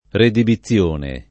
redibizione [ redibi ZZL1 ne ] s. f. (giur.)